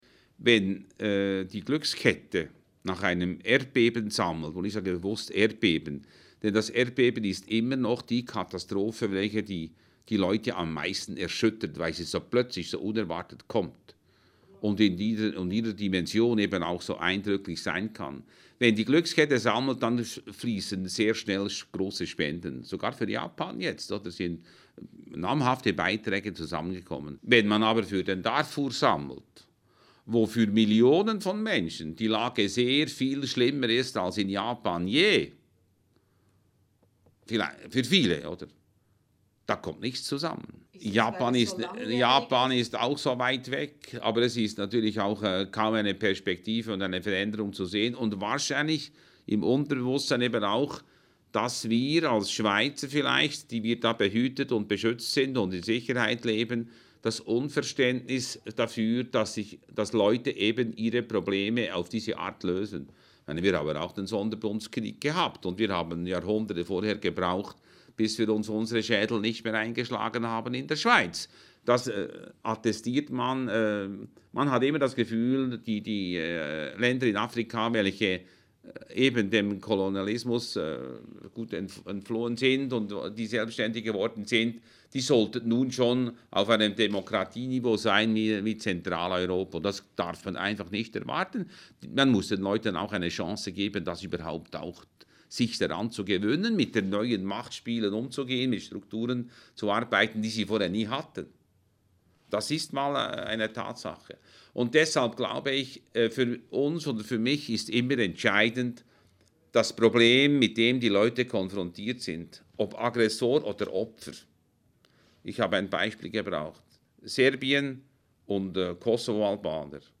Toni Frisch, Chef humanitäre Hilfe